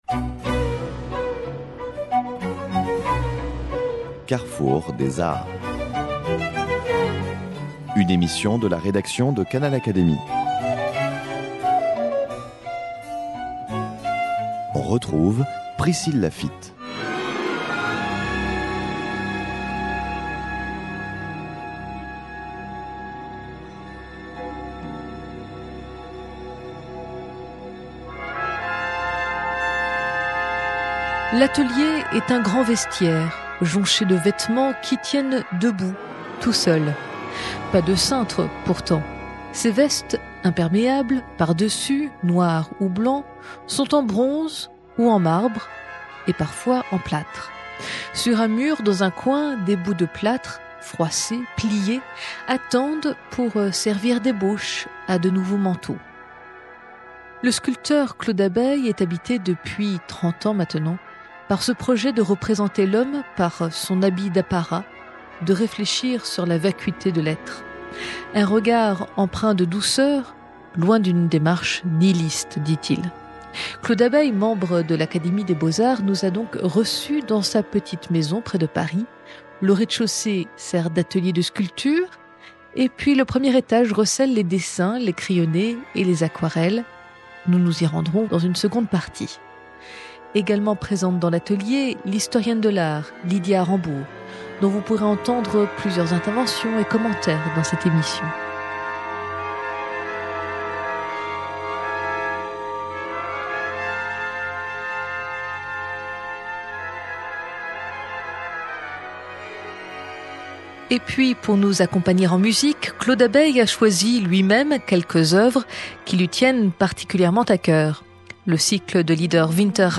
Dans l’atelier de sculpture